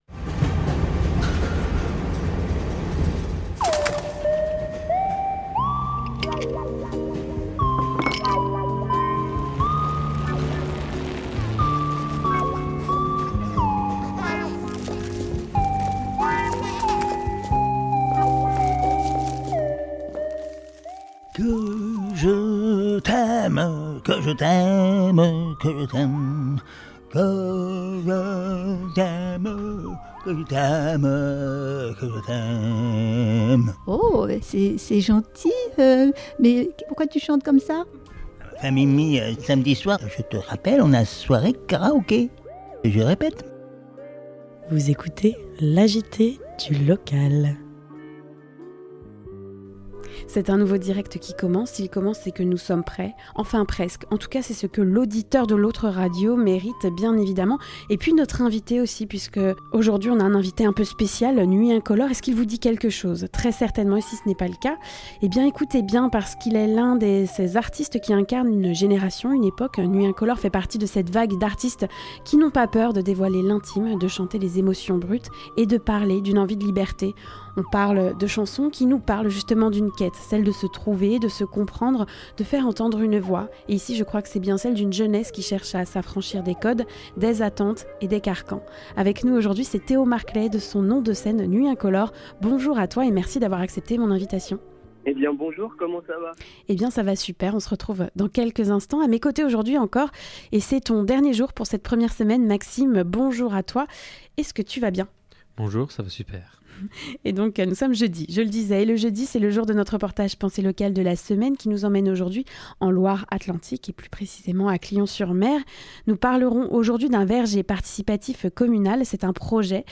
Le reportage Penser Local de la semaine : Un Verger pour l’Avenir, le projet de Mémoire fruitière en Pays de Retz